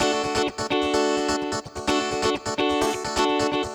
VEH3 Electric Guitar Kit 1 128BPM
VEH3 Electric Guitar Kit 1 - 11 D maj.wav